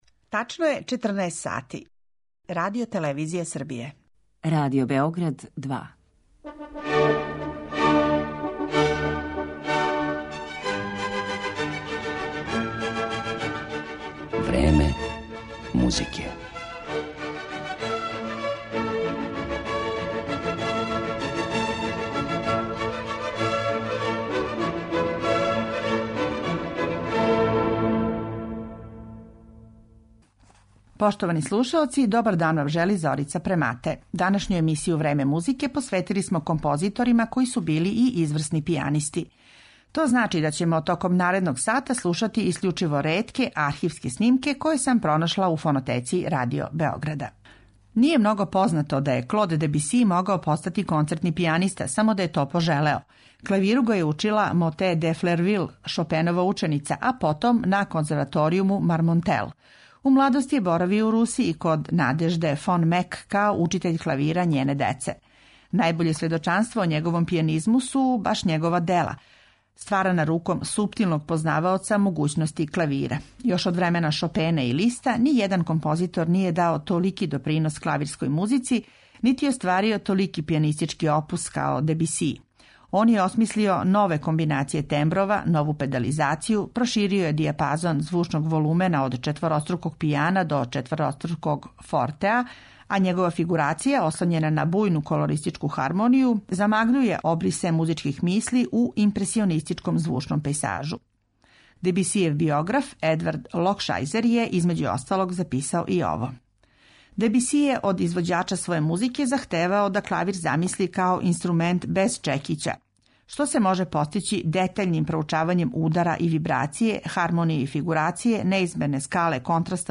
Композитори за клавиром
У емисији Време музике слушаћете ретке архивске снимке које су остварили велики композитори који су такође били и изврсни концертни пијанисти: Клод Дебиси, Сергеј Рахмањинов и Сергеј Прокофјев.